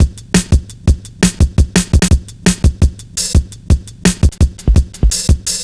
Index of /90_sSampleCDs/Zero-G - Total Drum Bass/Drumloops - 3/track 62 (170bpm)